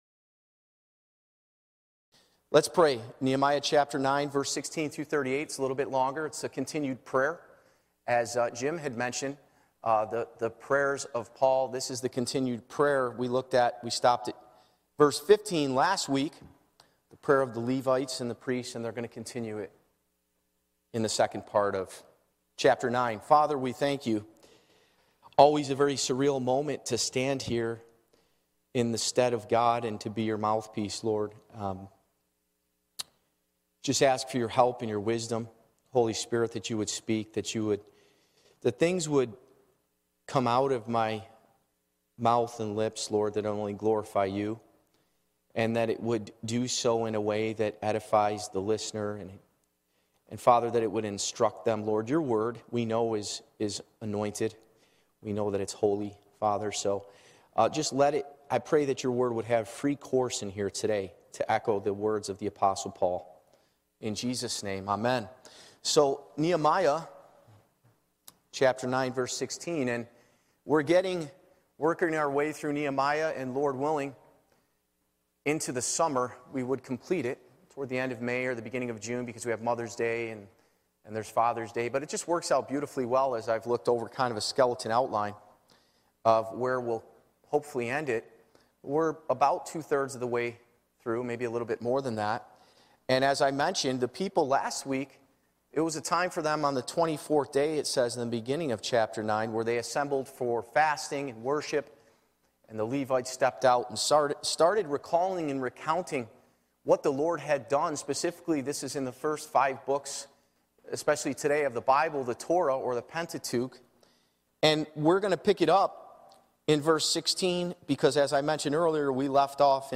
Church Location: Spencerport Bible Church
Live Recording Date: Sunday, April 23, 2023